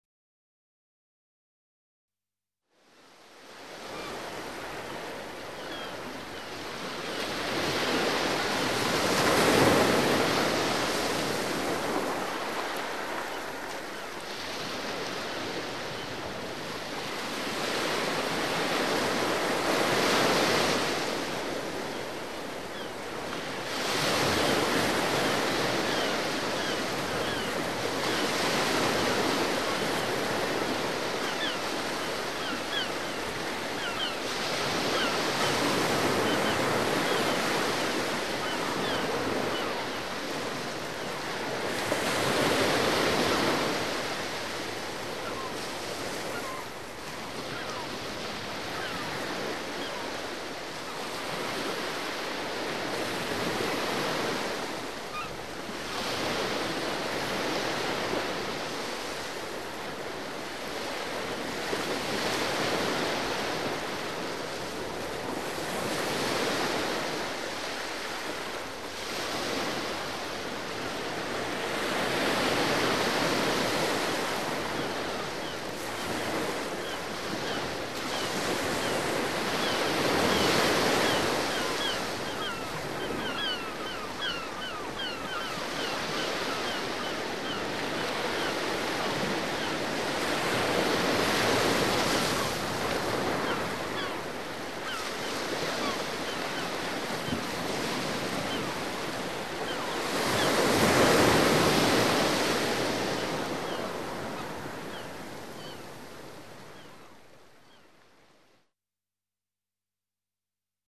Звуки морского прибоя
Шум прибоя и крики чаек: звуки природы у моря